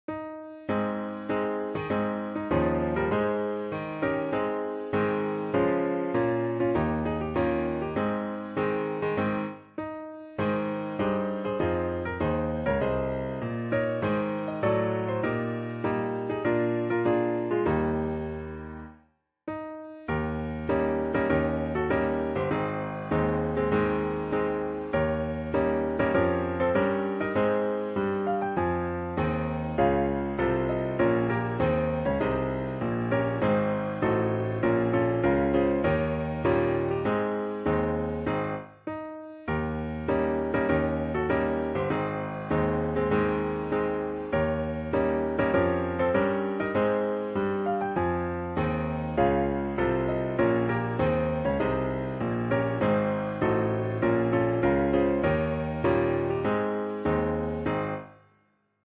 торжественная мелодия
Музыка Гимна Мадагаскара в исполнении на пианино